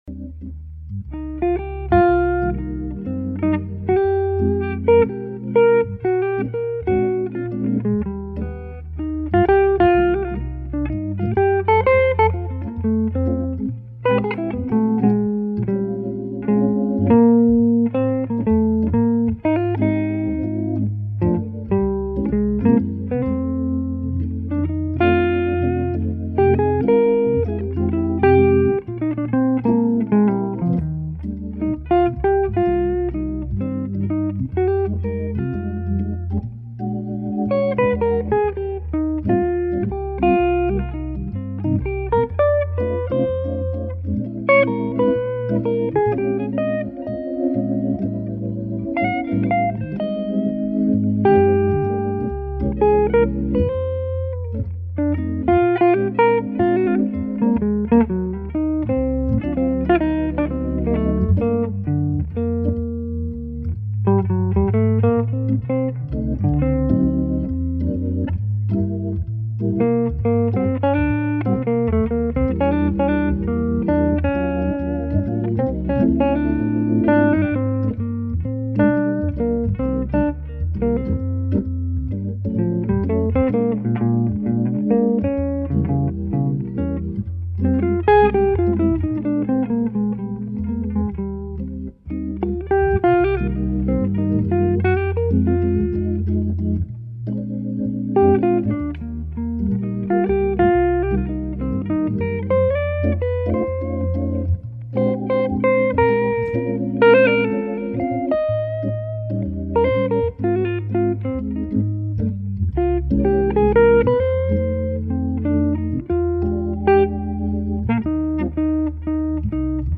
J'aime bien ce style assez cool.